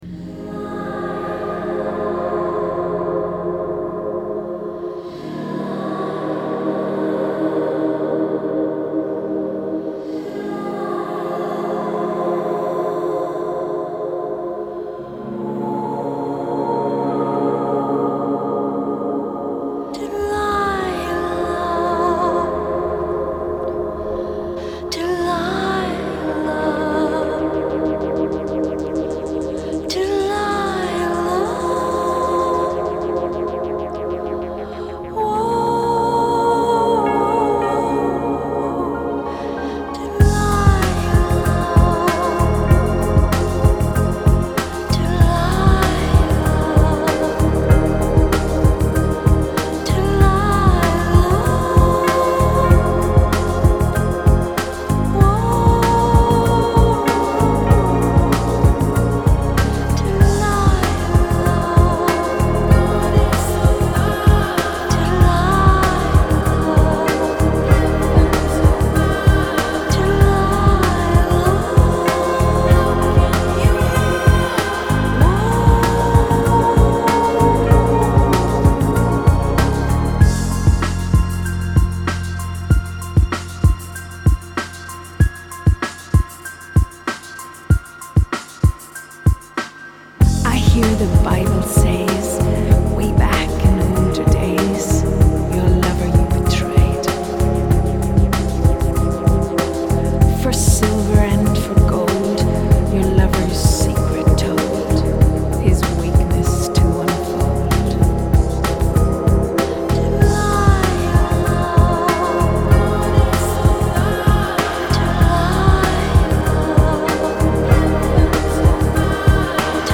那些音樂結合了愛爾蘭傳統的民間樂器，例如口簧的呼嘯聲和uilleann 管，以及鋼琴，還有電子樂、鼓樂。